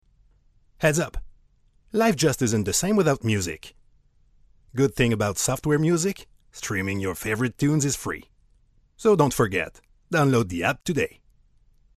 Commercial Demo
All this in a well-soundproofed cedar wardrobe that smells good!
Bass